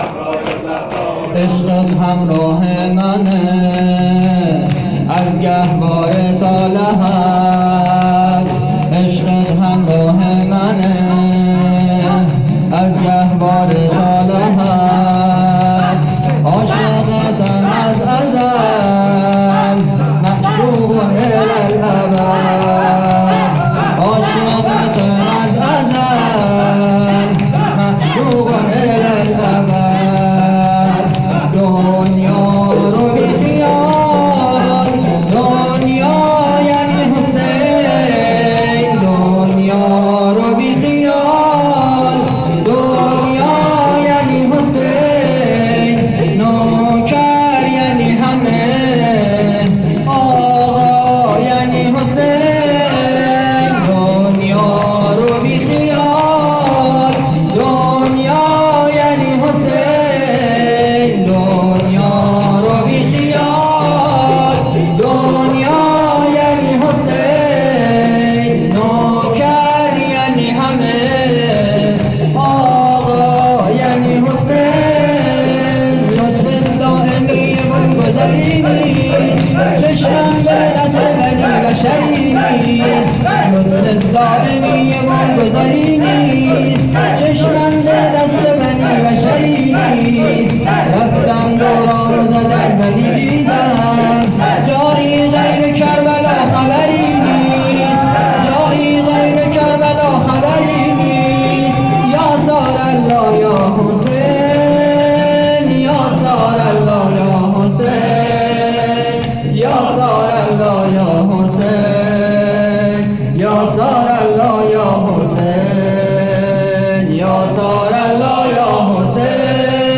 فاطمیه 1401